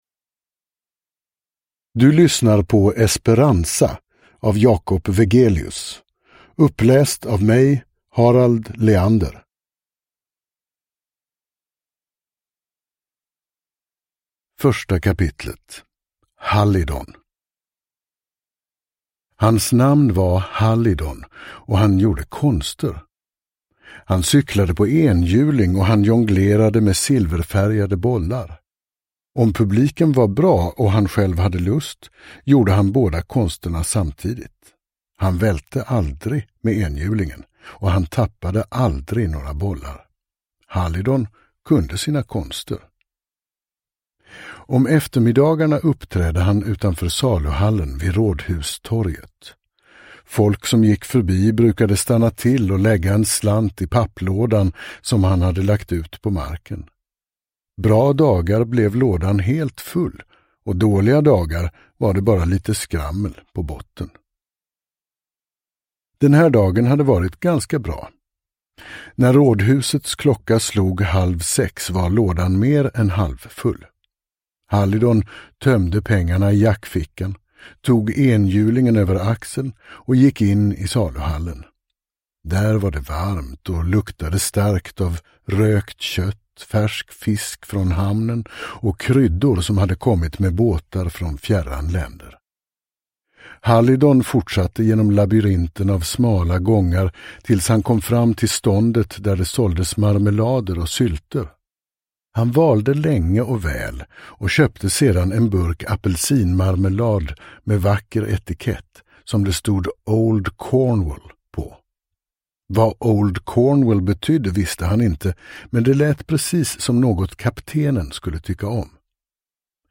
Esperanza – Ljudbok – Laddas ner